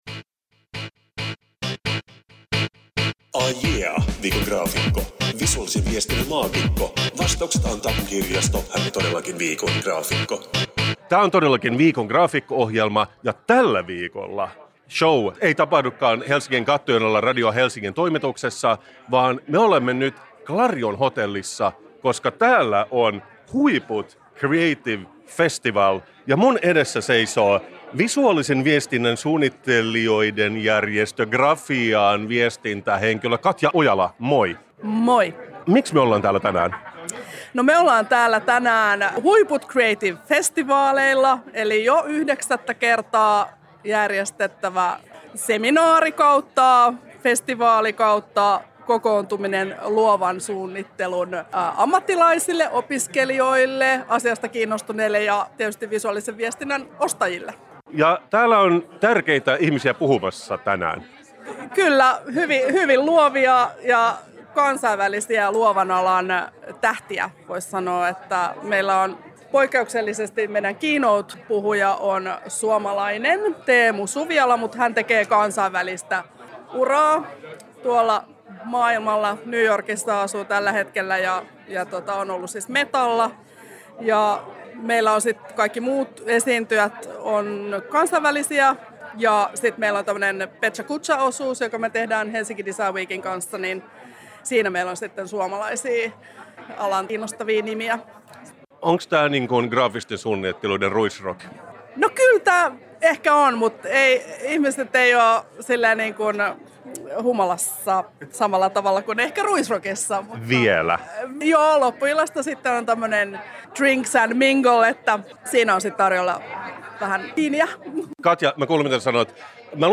Viikon graafikko-ohjelmassa on tällä viikolla JUHLAHUMUA kun ääneen pääsee poikkeuksellisesti kymmenisen suunnittelijaa viimeviikkoiselta HUIPUT CREATIVE FESTIVALILTA. Mitä mieltä fonttisuunnittelija Peter Bilak on emojeista? Miksi pensasmustikat tappavat luovuutta?